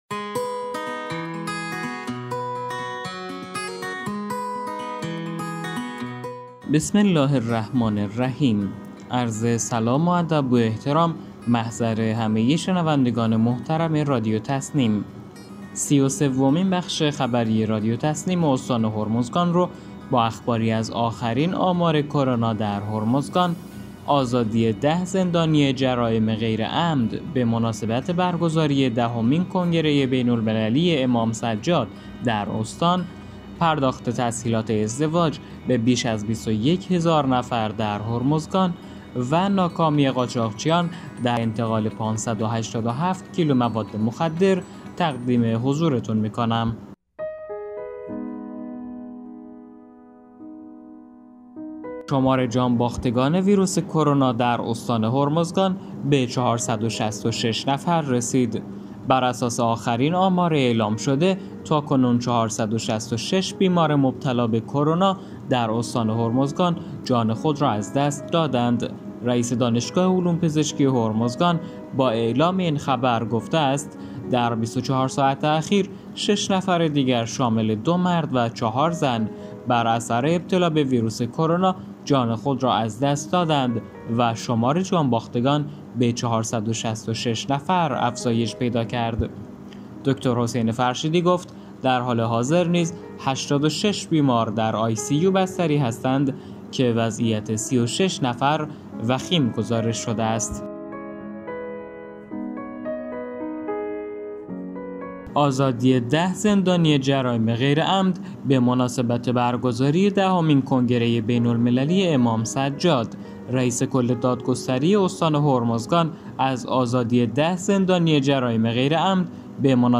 به گزارش خبرگزاری تسنیم از بندرعباس، سی‌ و سومین بخش خبری رادیو تسنیم استان هرمزگان با اخباری از آخرین آمار کرونا در هرمزگان، آزادی 10 زندانی جرائم غیرعمد به مناسبت برگزاری دهمین کنگره بین‌المللی امام سجاد(ع)، پرداخت تسهیلات ازدواج به بیش از 21 هزار نفر در هرمزگان و ناکامی قاچاقچیان در انتقال 857 کیلو مواد مخدر منتشر شد.